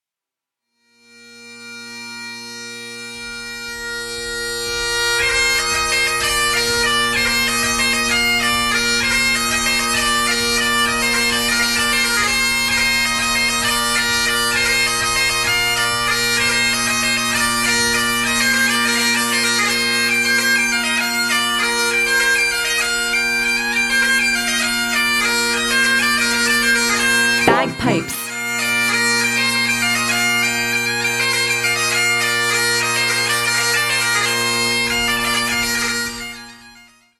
gaita.mp3